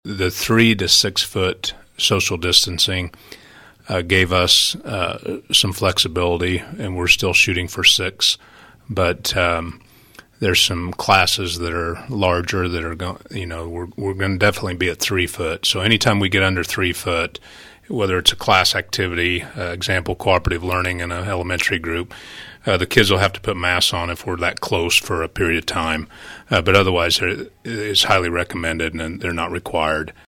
School administrators were part of separate interviews on KVOE the past few days to update their situations.